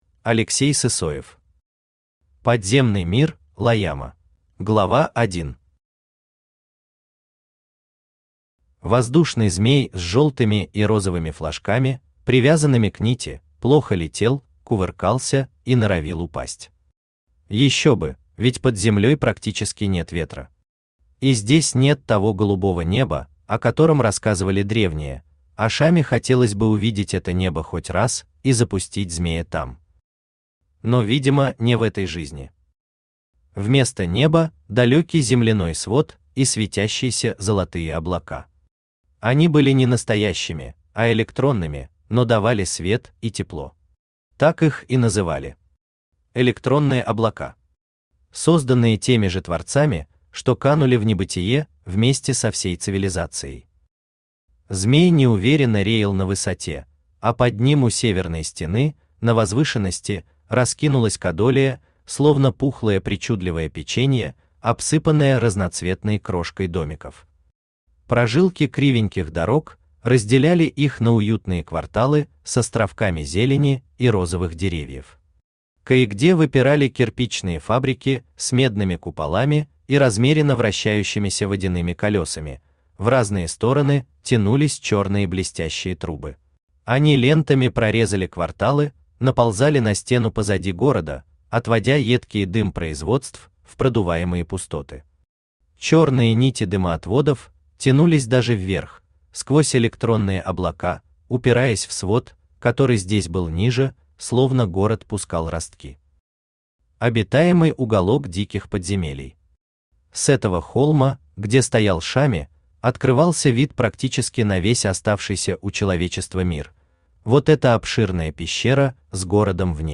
Aудиокнига Подземный мир Лайама Автор Алексей Николаевич Сысоев Читает аудиокнигу Авточтец ЛитРес.